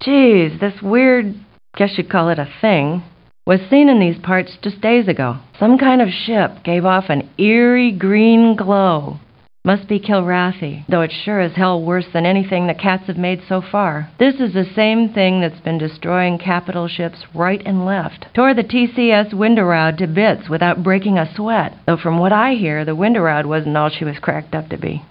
Bartender_Rumor_20_Female.mp3